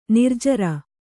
♪ nirjara